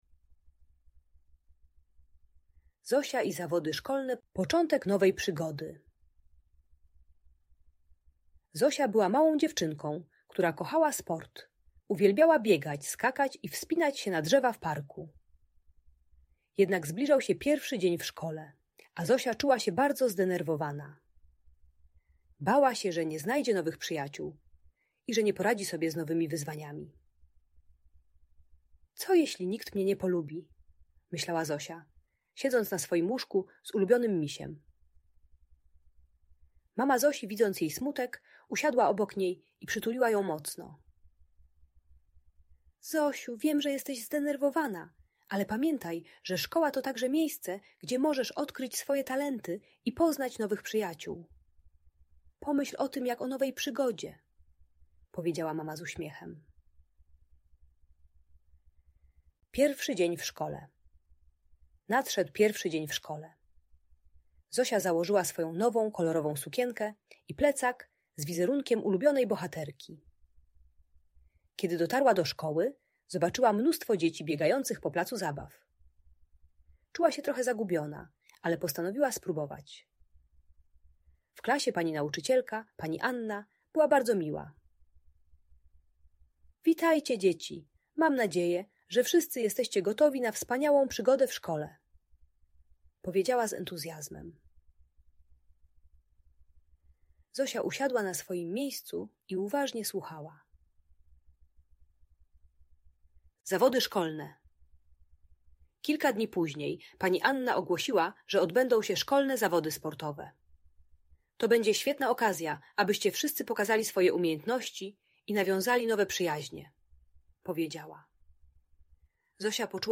Historia Zosi i Zawodów Szkolnych - Audiobajka dla dzieci